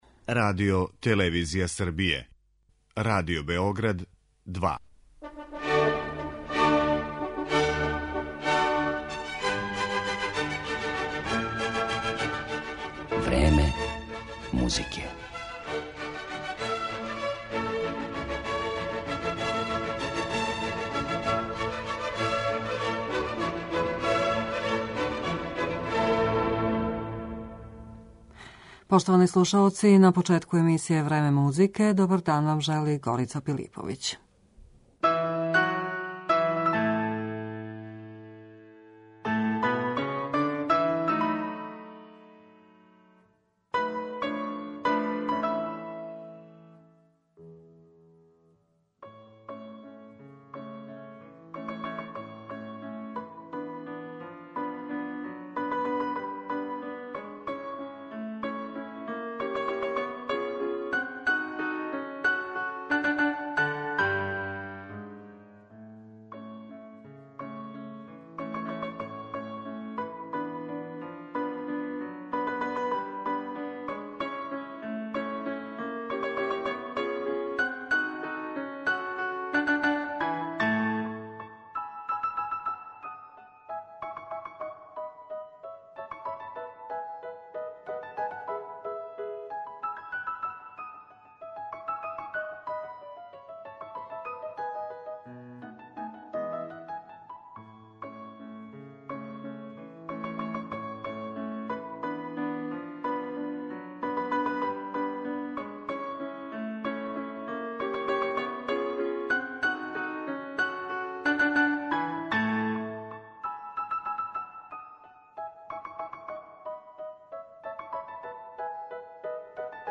Инструментална дела Гаетана Доницетија